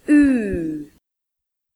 ew